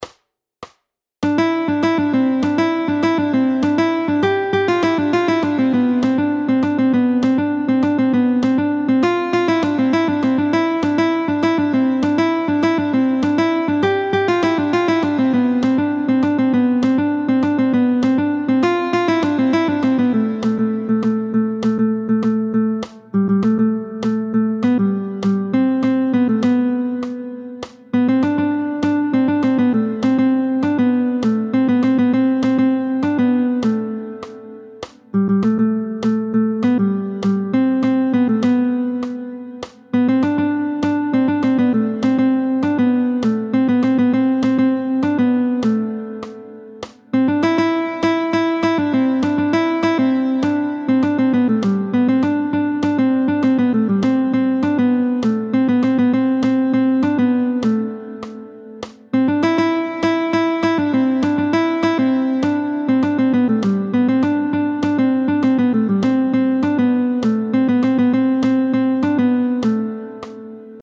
√ برای ساز گیتار | سطح متوسط